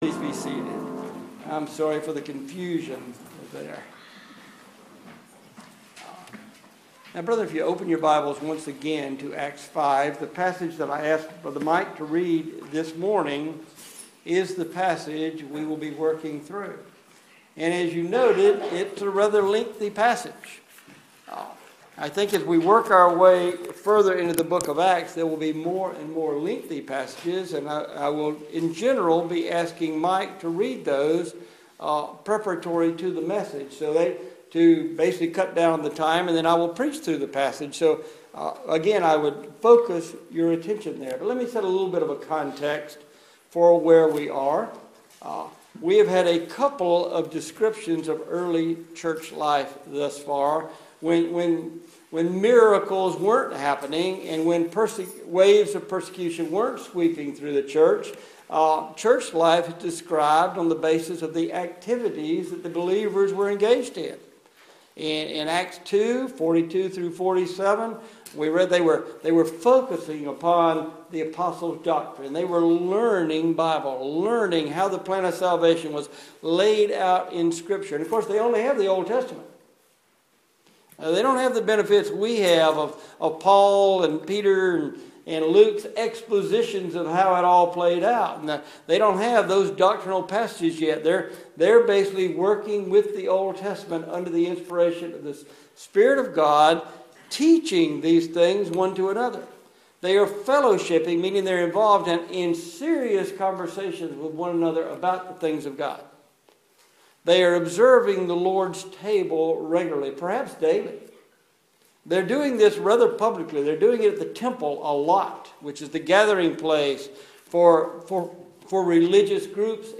Acts 5:17-42 Service Type: Sunday Morning « Reality or Hypocricy?